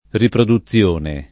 [ riprodu ZZL1 ne ]